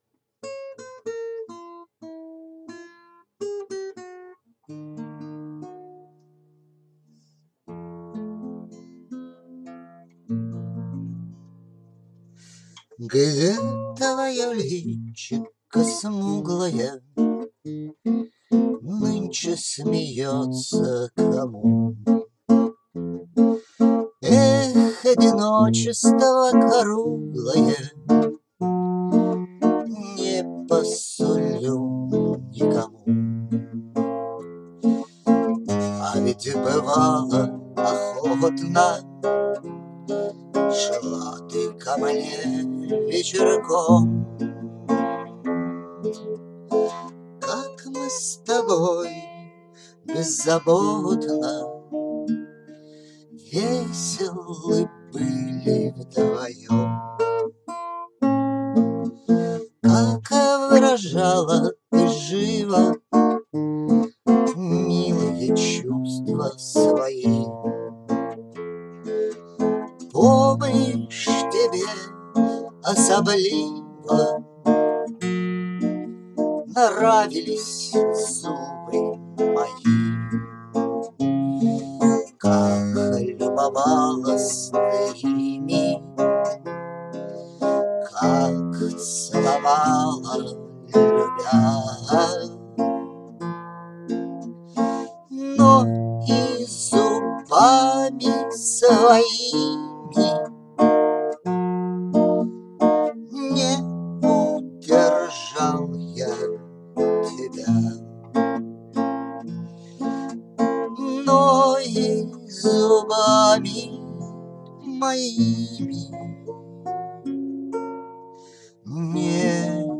Из хулиганских побуждений решил тоже отметить юбилей Некрасова. Пришлось даже струну покупать, лопнула.